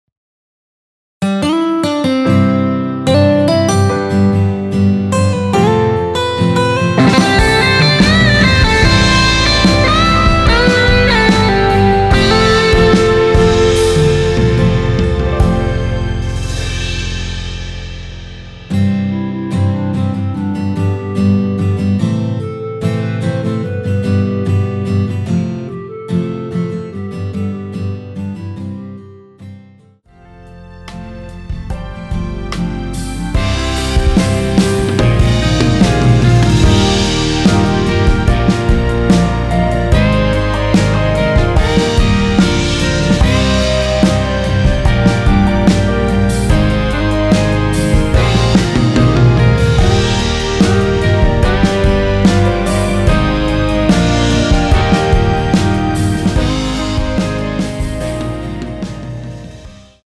원키에서(-7)내린 멜로디 포함된 MR입니다.(미리듣기 확인)
멜로디 MR이란
앞부분30초, 뒷부분30초씩 편집해서 올려 드리고 있습니다.
중간에 음이 끈어지고 다시 나오는 이유는